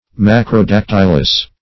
Search Result for " macrodactylous" : The Collaborative International Dictionary of English v.0.48: Macrodactylic \Mac`ro*dac*tyl"ic\, Macrodactylous \Mac`ro*dac"tyl*ous\, a. (Zool.) Having long toes.
macrodactylous.mp3